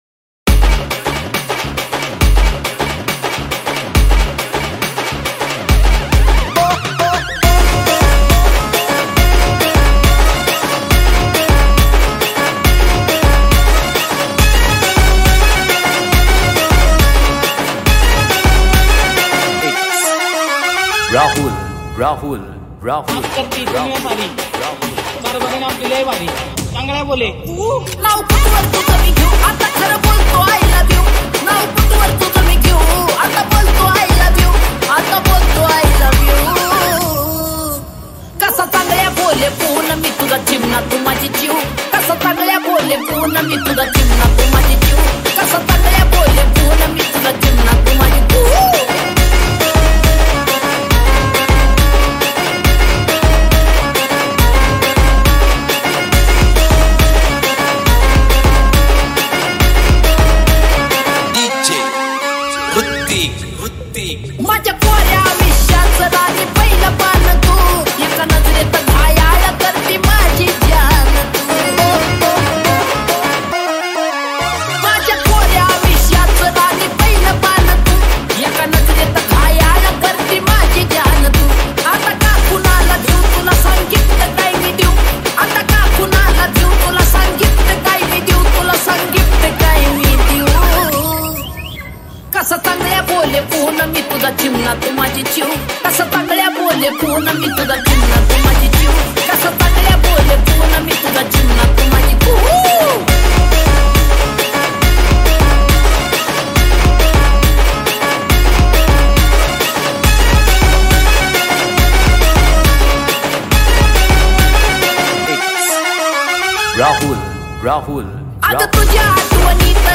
• Category: Marathi Djs Remix